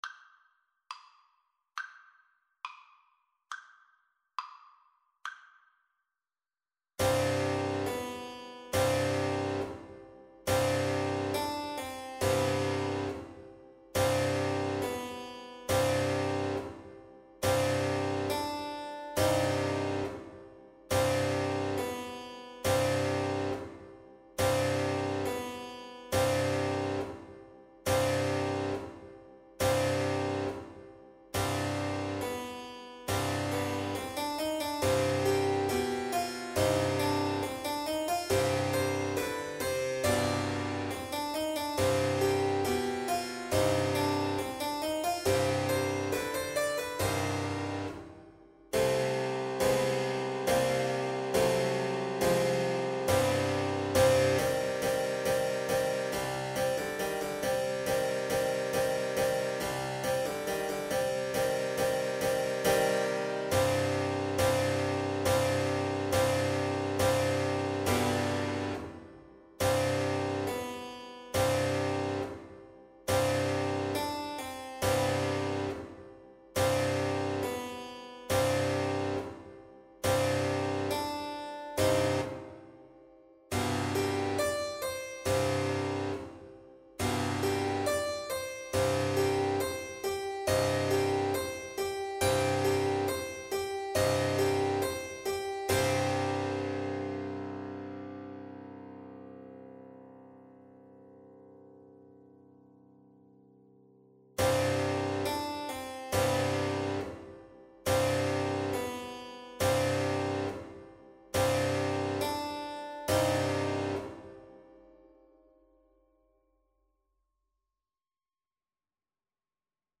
Classical Rameau, Jean-Philippe Tambourin Suite in E minor, RCT 2, No. 8 Trumpet version
Play (or use space bar on your keyboard) Pause Music Playalong - Piano Accompaniment Playalong Band Accompaniment not yet available transpose reset tempo print settings full screen
Trumpet
2/2 (View more 2/2 Music)
C5-G6
Eb major (Sounding Pitch) F major (Trumpet in Bb) (View more Eb major Music for Trumpet )
= 200 Allegro molto (View more music marked Allegro)
Classical (View more Classical Trumpet Music)